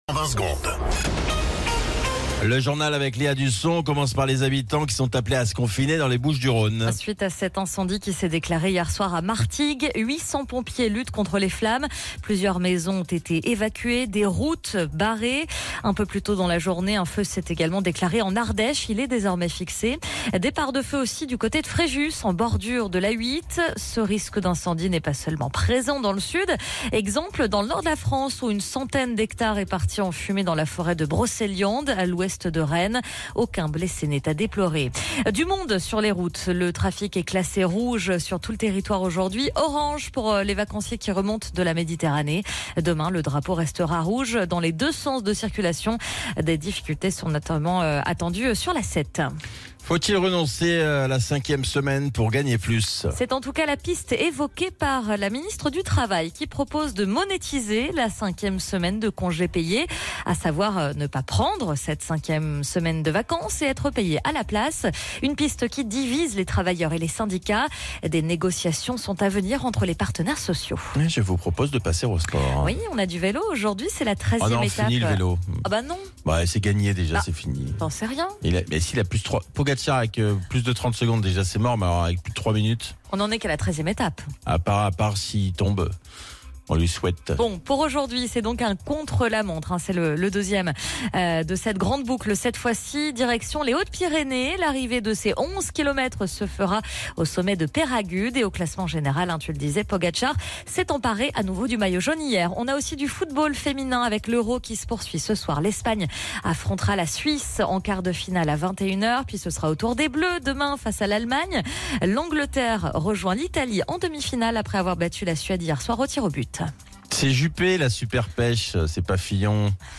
Flash Info National 18 Juillet 2025 Du 18/07/2025 à 07h10 .